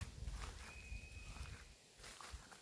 Bird Aves sp., Aves sp.
Administratīvā teritorijaSalacgrīvas novads
StatusVoice, calls heard
Notes Balss lidojumā, sauciena avots attālinājās. Radās iespaids, ka tika iztraucēts no tuvējās mežmalas.